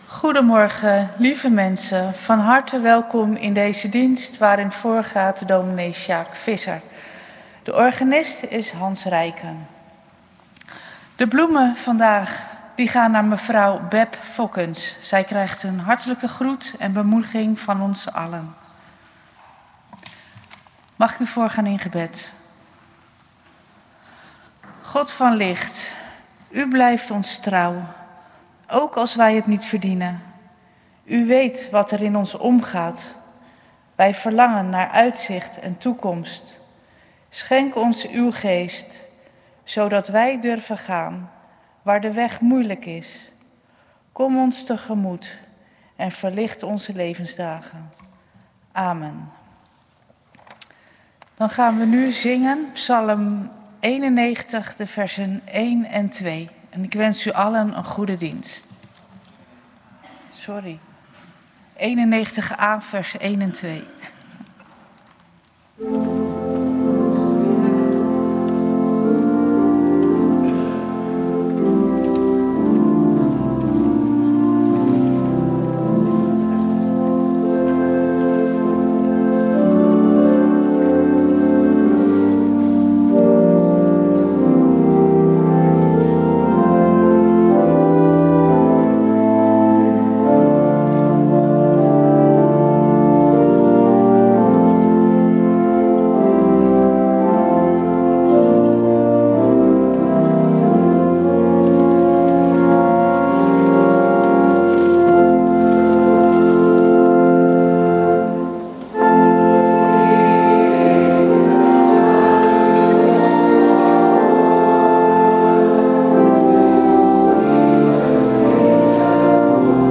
Kerkdienst geluidsopname